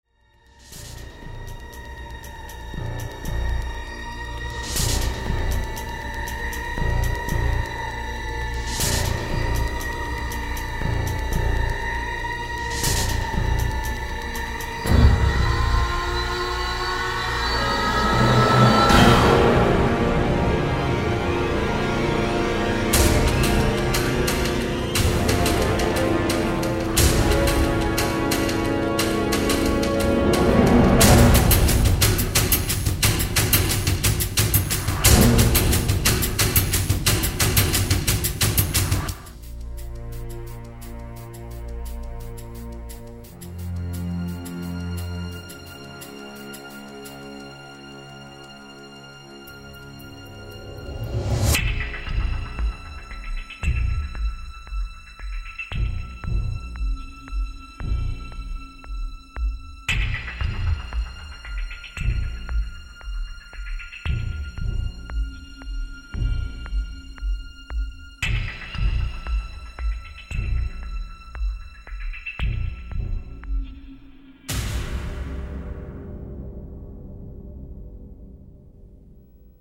Thể loại nhạc chuông: Nhạc tin nhắn